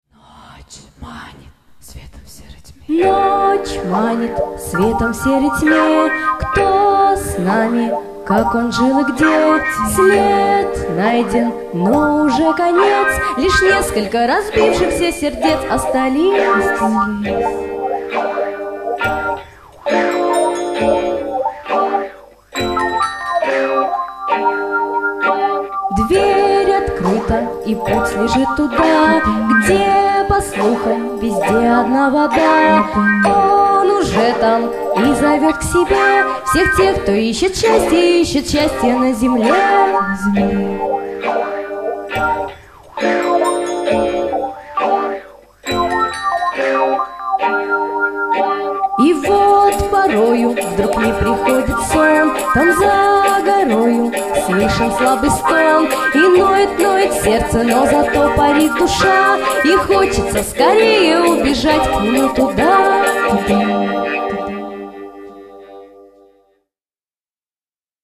Песни с концертов: